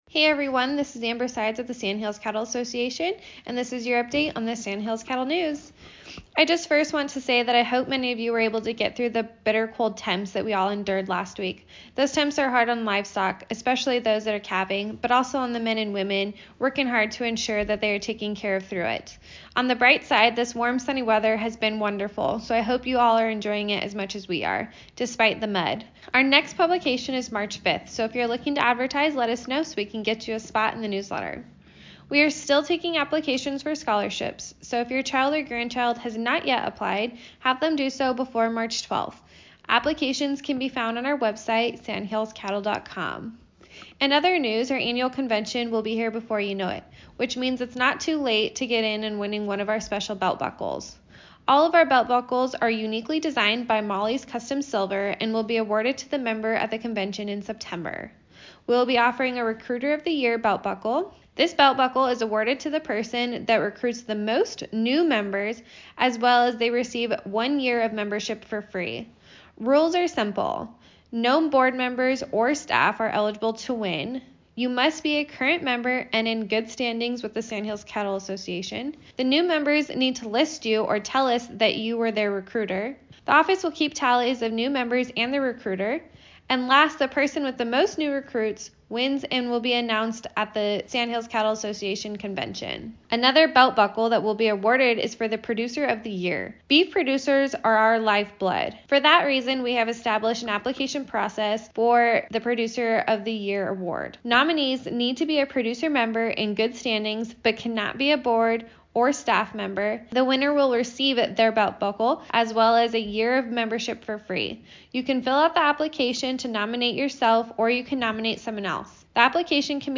Radio Spot for February 25, 2021